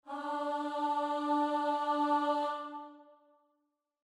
starting_note.mp3